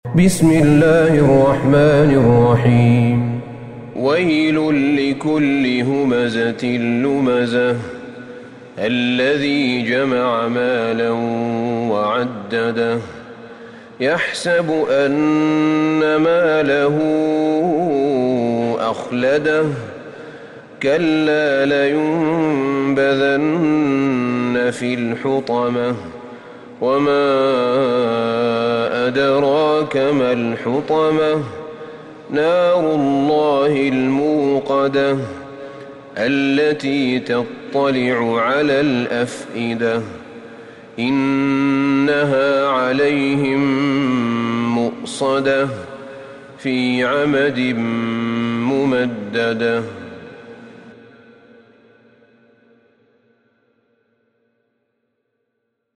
سورة الهمزة Surat Al-Humazah > مصحف الشيخ أحمد بن طالب بن حميد من الحرم النبوي > المصحف - تلاوات الحرمين